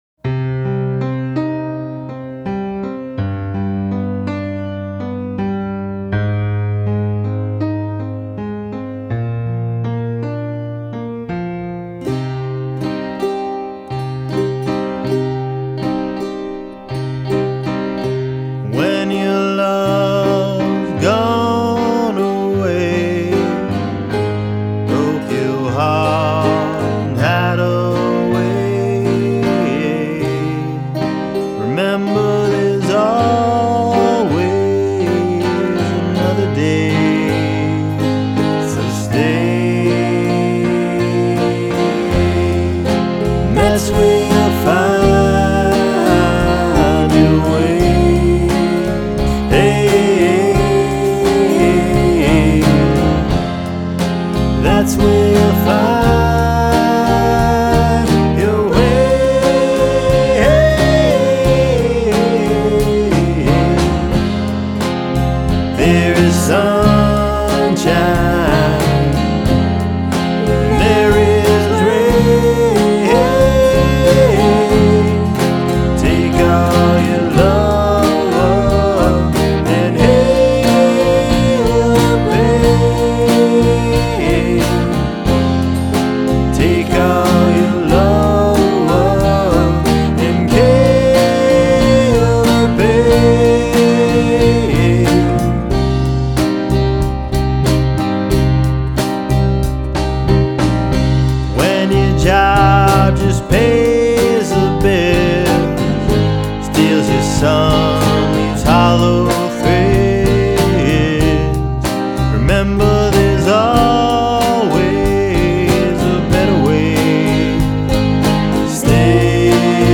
boy-girl piano/guitar avant-garde sing along music